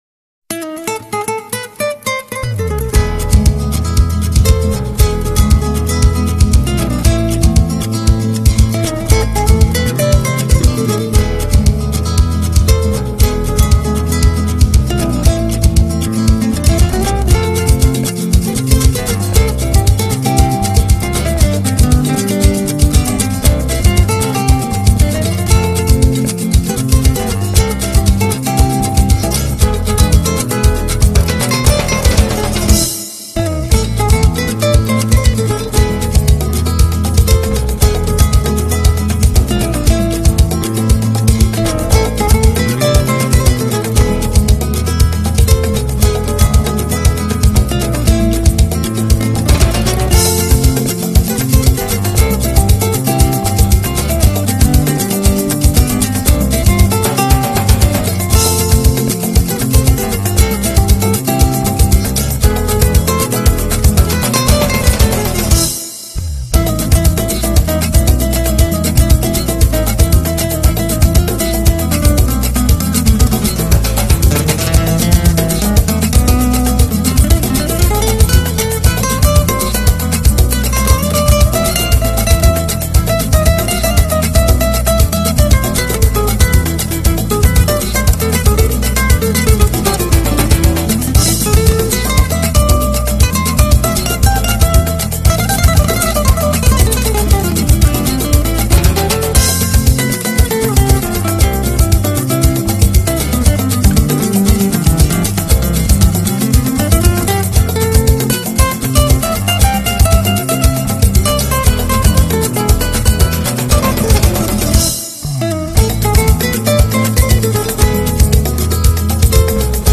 新世纪休闲轻音乐
24首世界各国经典清新优美新世纪休闲轻音乐。